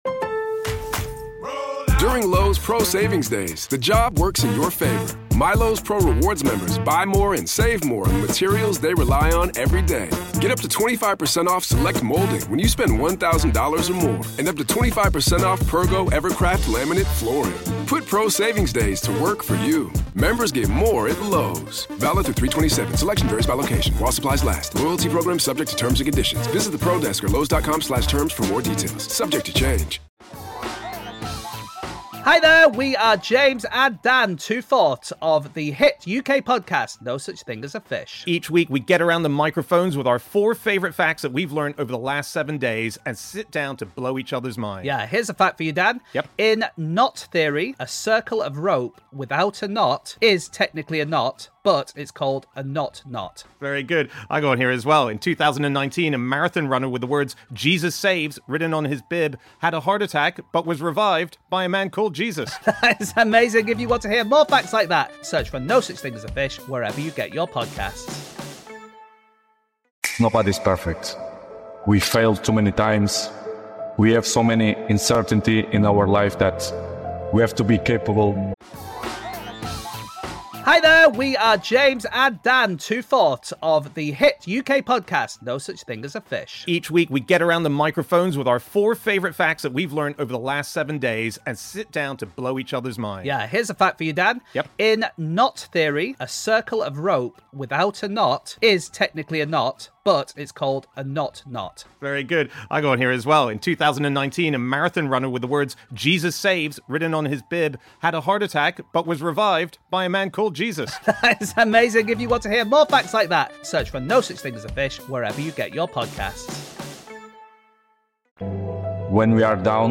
In this powerful compilation, you’ll hear motivational speeches, raw insights, and life advice that will leave you speechless.